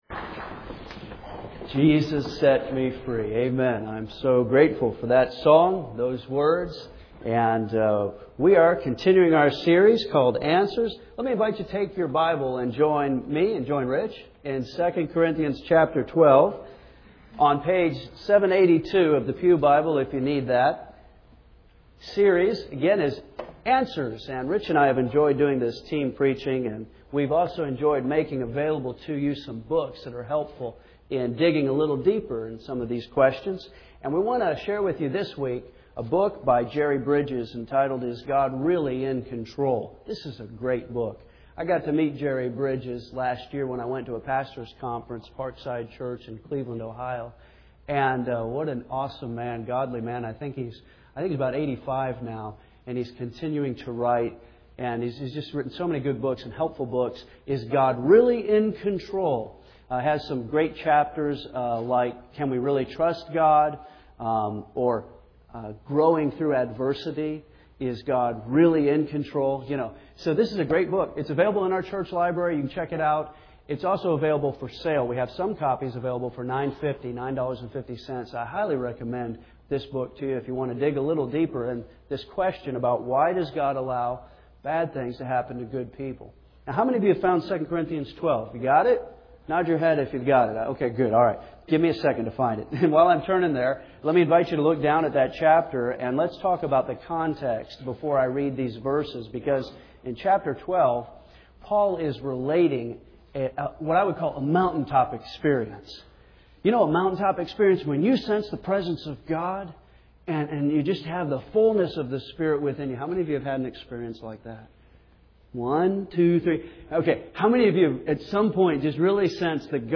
(2 Corinthians 12:7-10) Series: Answers (4 of 5) Team Preaching with Revs.